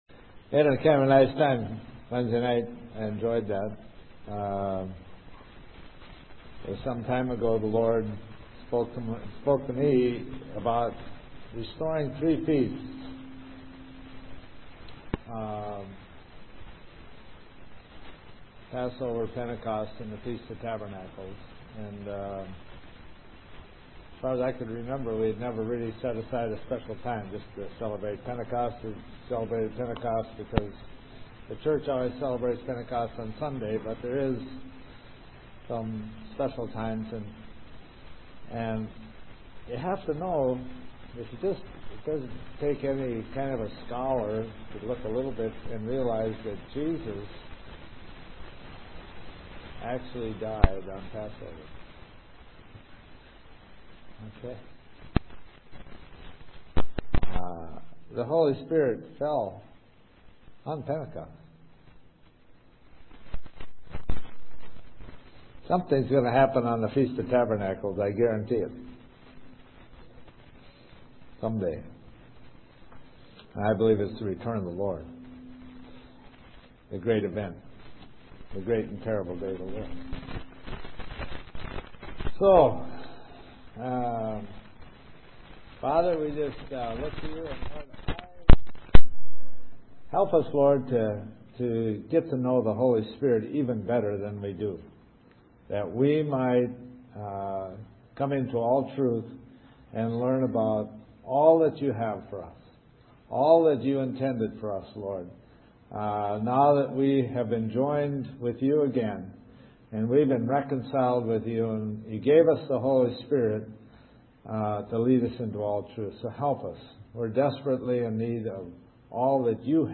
This entry was posted in sermons .